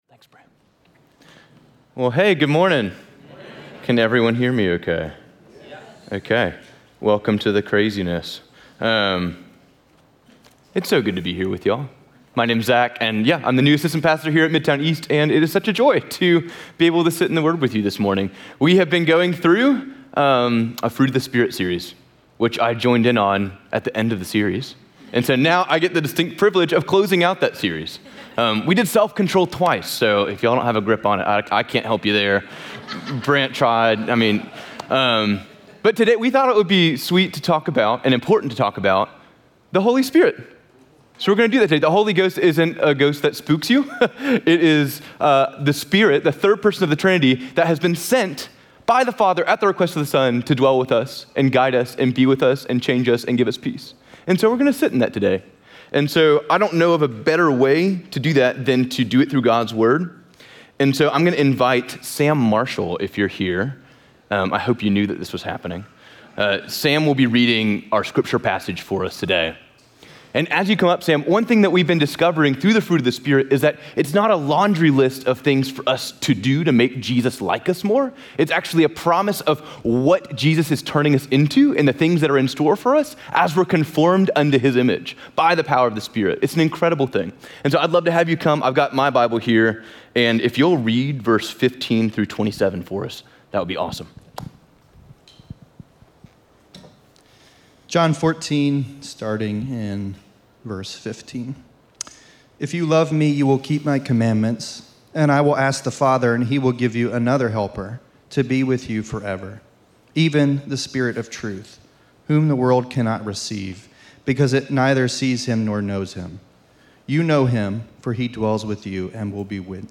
Midtown Fellowship East Nashville Sermons Podcast - Holy Spirit Sent To Us | Free Listening on Podbean App